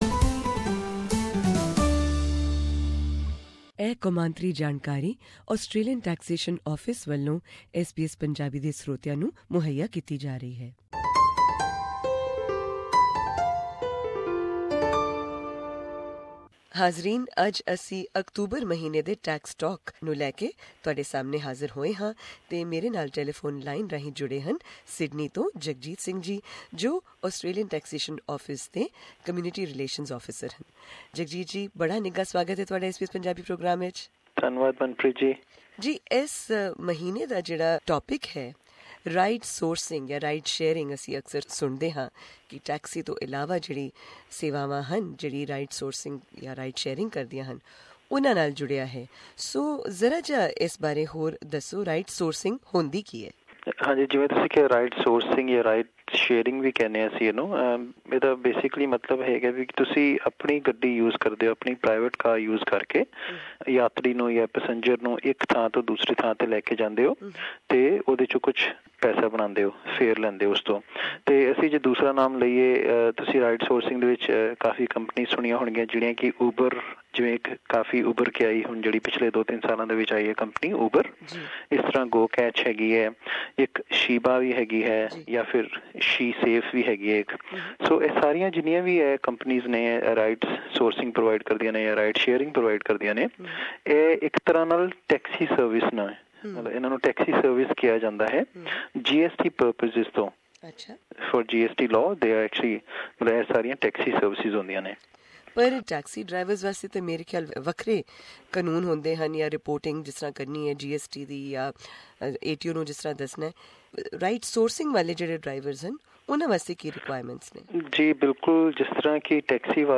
The following community information is brought to you by the Australian Taxation Office.
ATO provides tax and superannuation information in Punjabi every month on SBS Radio Source: ATO